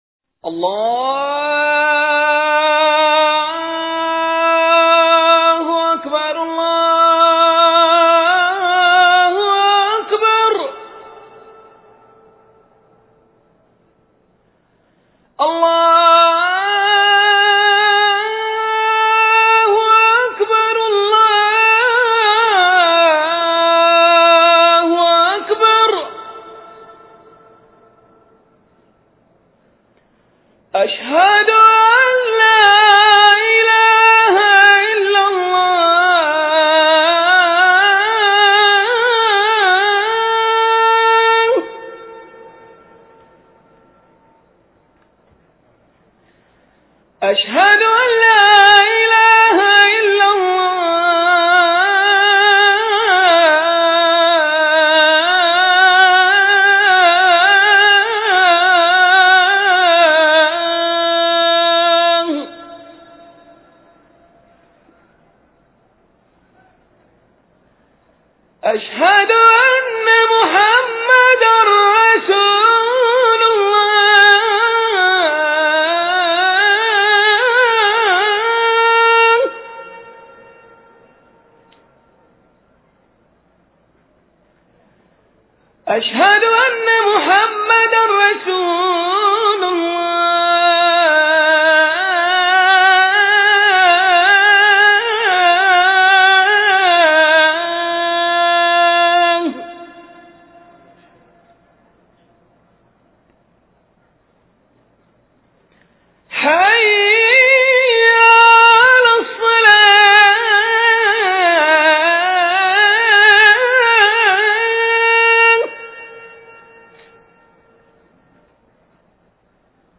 Der Athan
beim Hören des Athan (erster Gebetsruf)